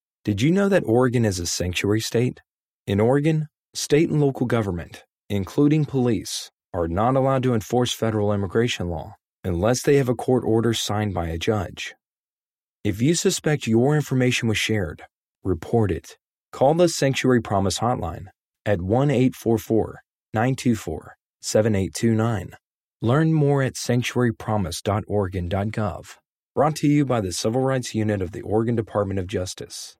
English PSA Audio only - 30 sec